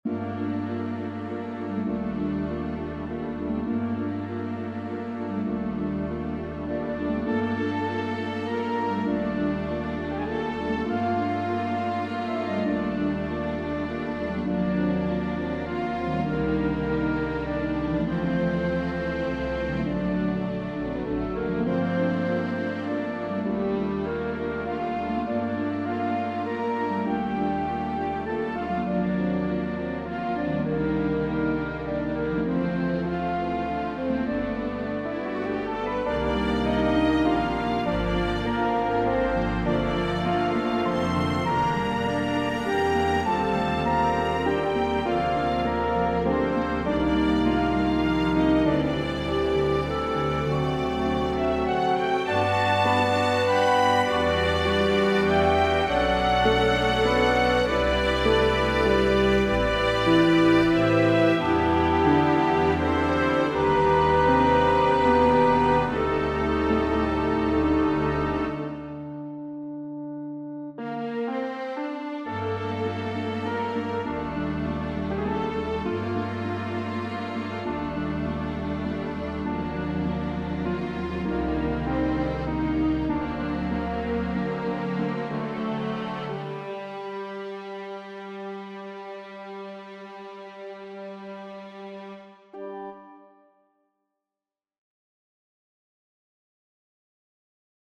1st violins, 2 violins, violas, cellos, basses .